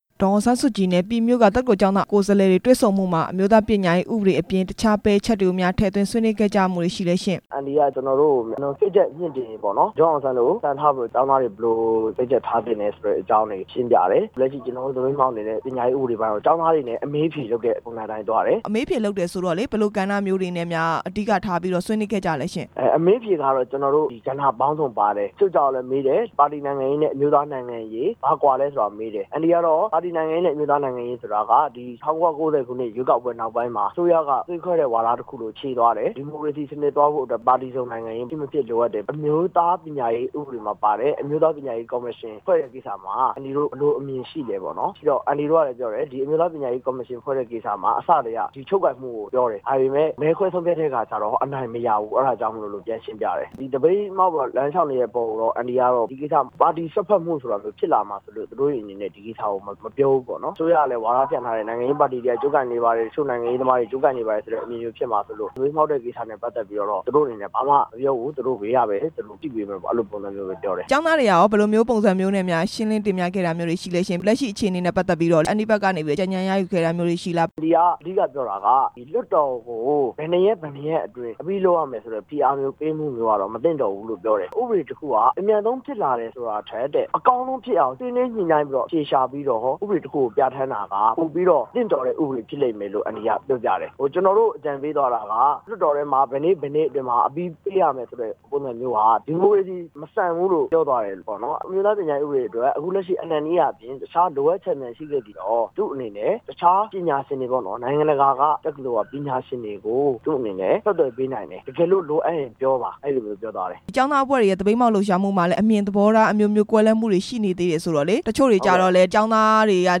ဒေါ်အောင်ဆန်းစုကြည်နဲ့ ပြည်မြို့က ကျောင်းသားတွေ တွေ့ဆုံတဲ့အကြောင်း မေးမြန်းချက်